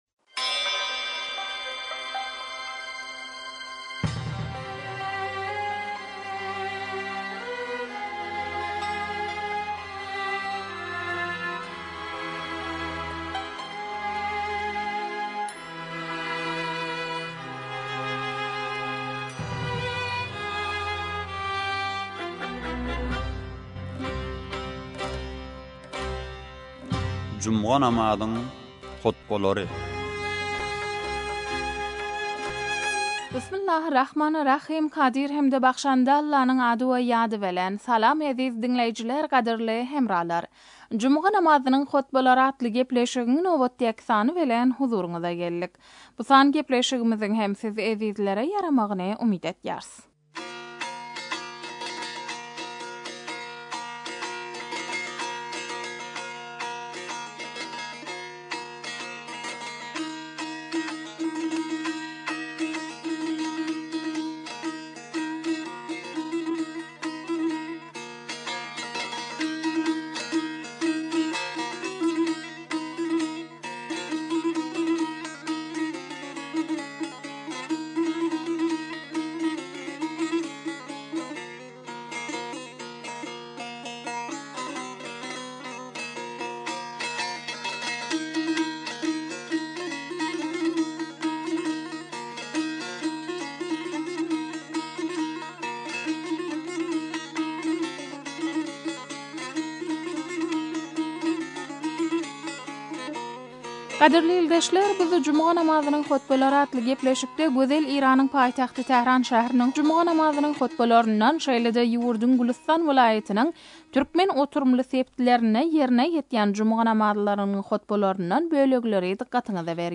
Juma namzynyň hotbalary